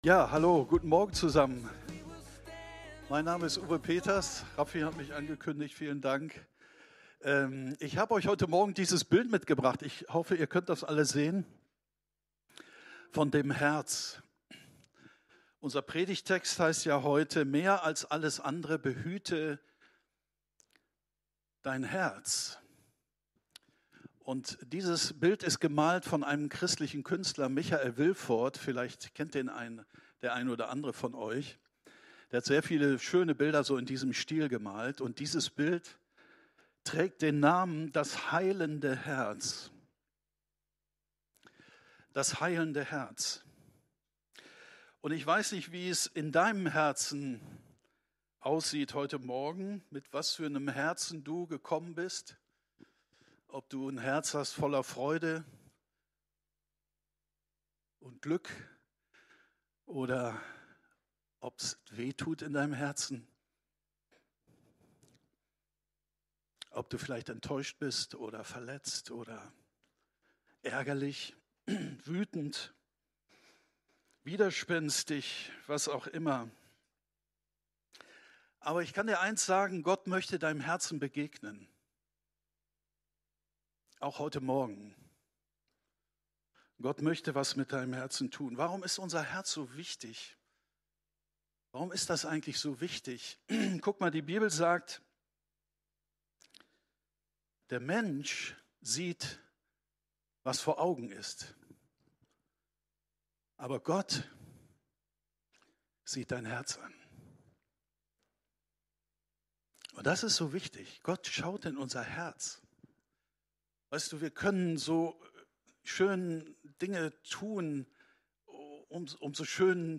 Unsere Predigt vom 09.07.23 Predigtserie: Weisheiten fürs Leben Folge direkt herunterladen